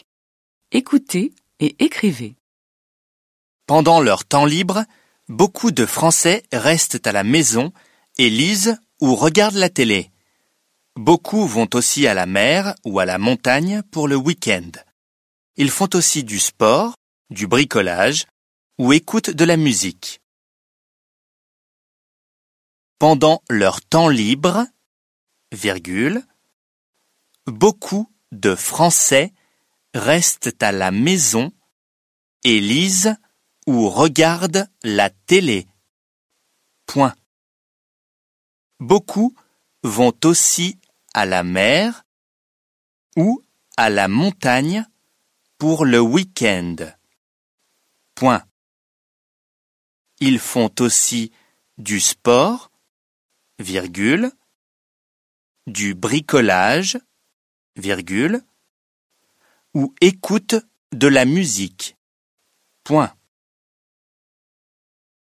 دیکته - مبتدی